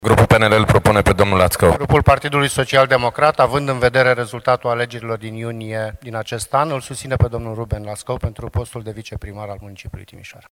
Pe lângă voturile colegilor săi din Alianța Dreapta Unită, el a avut și sprijinul PNL și PSD, prin vocea liderilor de grup Adrian Lulciuc (PNL) și Sorin Ionescu (PSD).